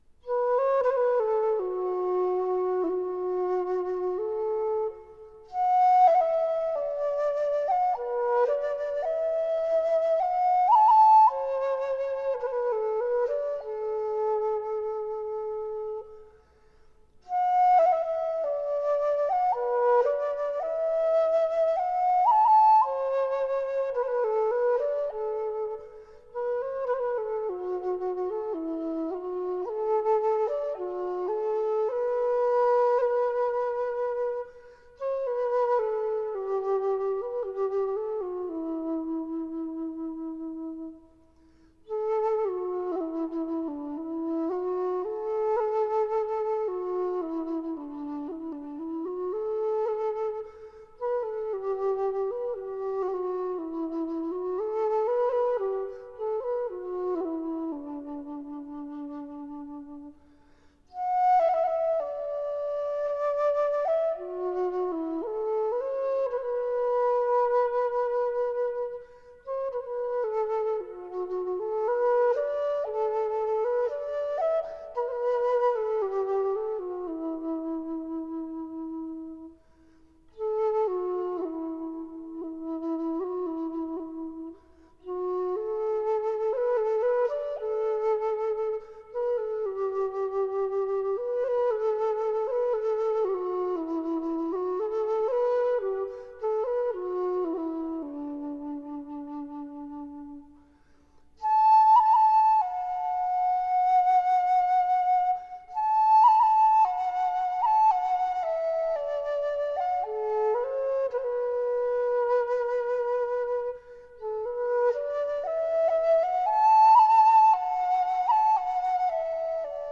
◎製作群 ： 演出：笛子、簫｜